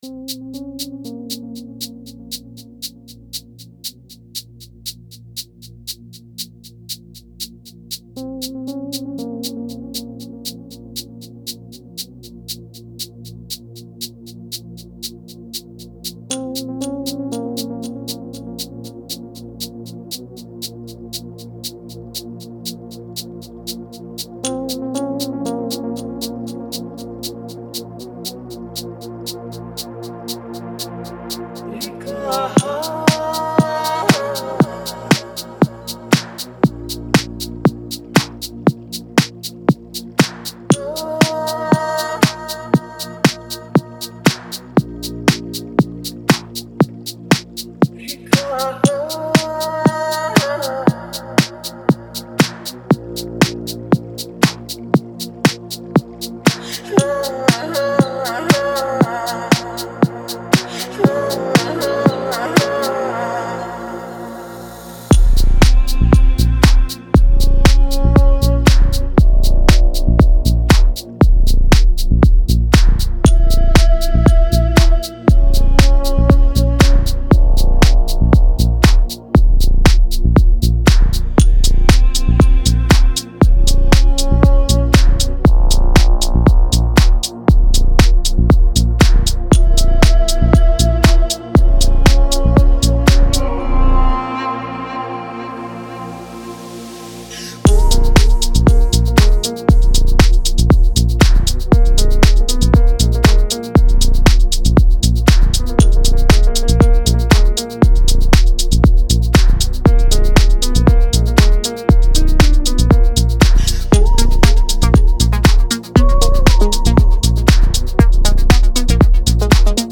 Жанр: house